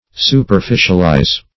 Search Result for " superficialize" : The Collaborative International Dictionary of English v.0.48: Superficialize \Su`per*fi"cial*ize\, v. t. To attend to, or to treat, superficially, or in a shallow or slighting way.